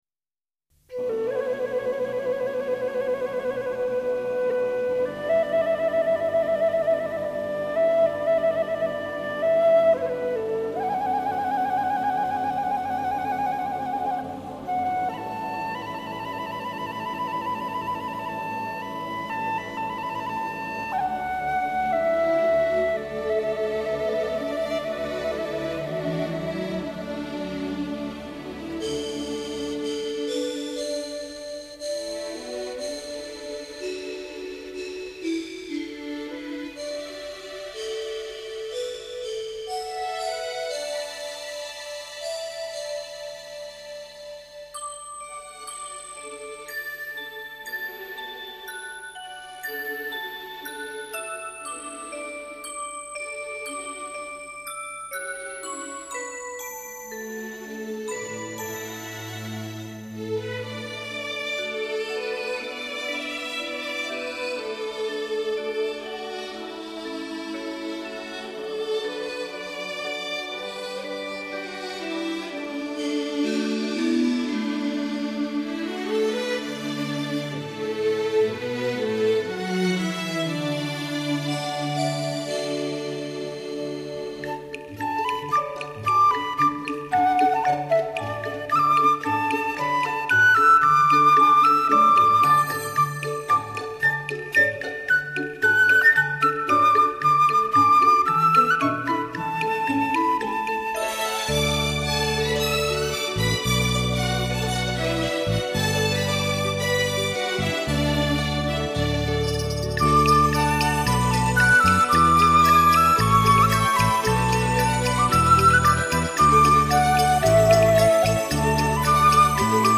专辑类型：纯音乐